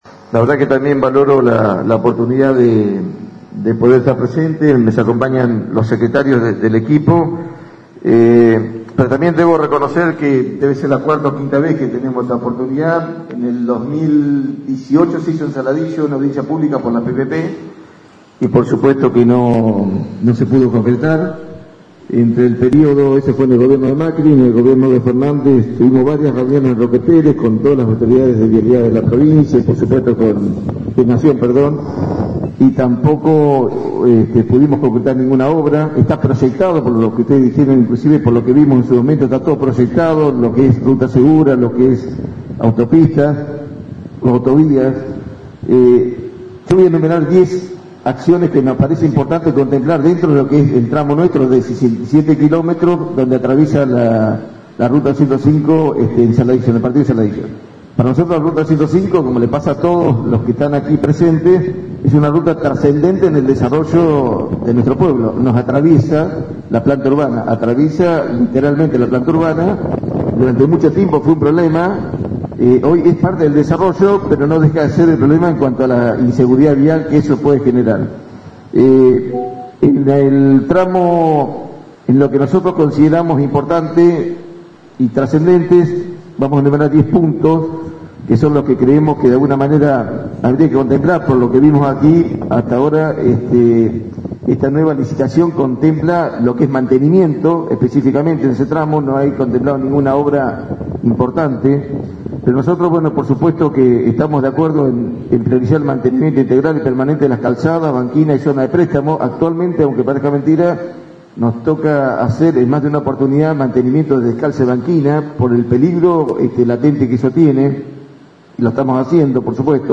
(incluye audios) Ayer miércoles en el Centro Cultural de la ciudad San Miguel del Monte se llevó a cabo la audiencia pública Zona Sur.
Intendente José Luis Salomón (Saladillo): el jefe comunal de la vecina localidad también participó de la audiencia pública requiriendo obras para la ruta nacional 205.